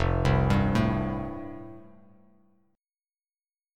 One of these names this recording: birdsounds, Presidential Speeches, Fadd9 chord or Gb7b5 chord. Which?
Fadd9 chord